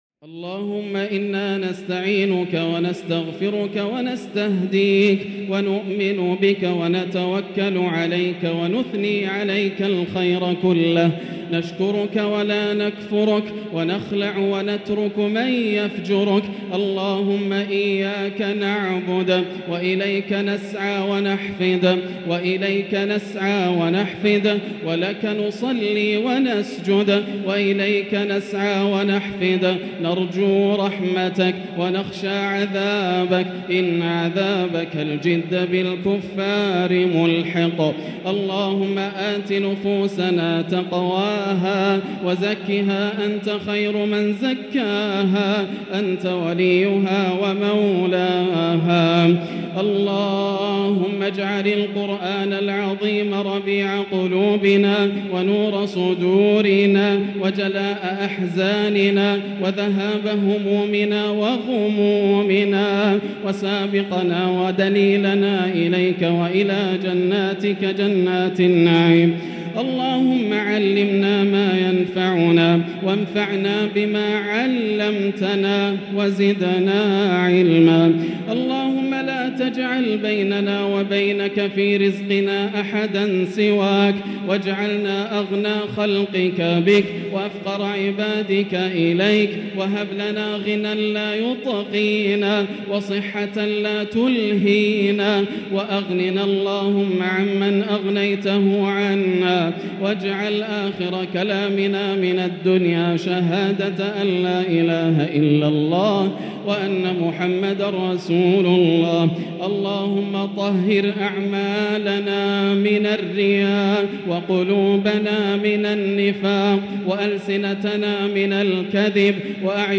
دعاء القنوت ليلة 9 رمضان 1444هـ | Dua 9 st night Ramadan 1444H > تراويح الحرم المكي عام 1444 🕋 > التراويح - تلاوات الحرمين